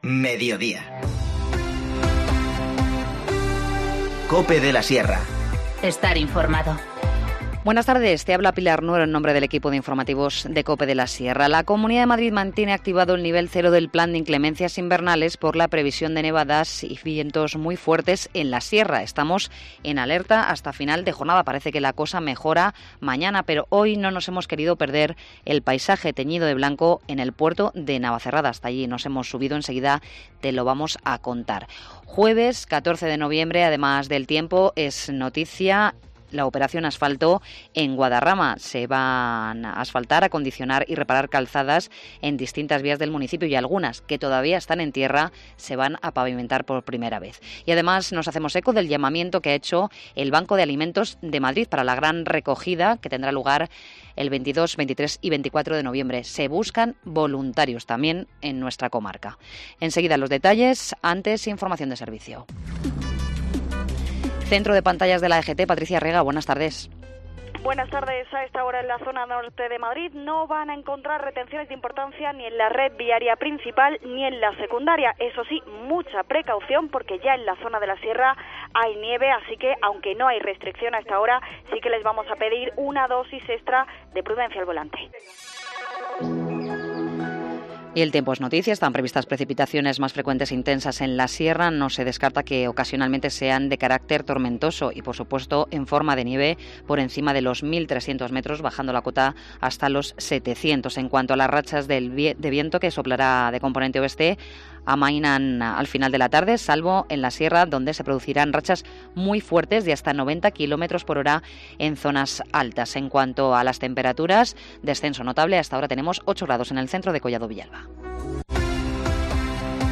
Informativo Mediodía 14 noviembre 14:20h